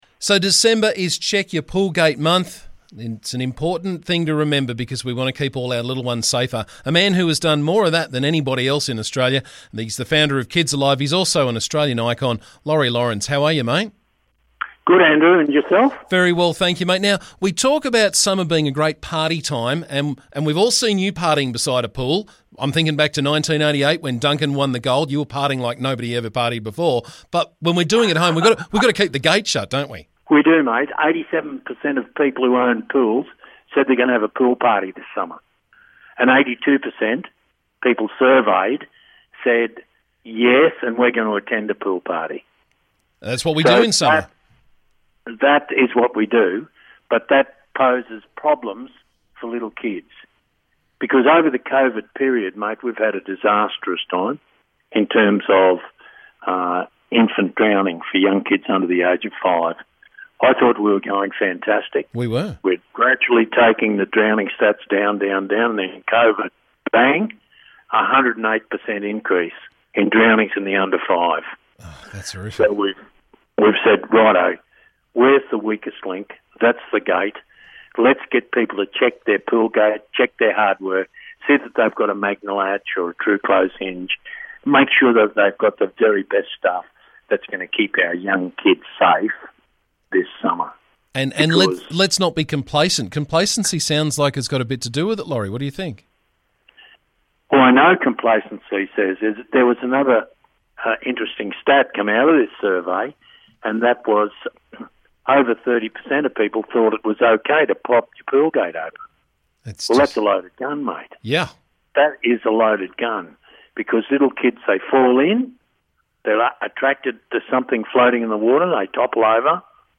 This month is ‘Check Your Pool Gate Month’ , so we spoke with Founder of KidsAlive and Aussie icon, Laurie Lawrence to discuss the research and the Perfect Pool Party Guide.... and have a little chat about the Aussie swimmers in Japan on The Sideline View.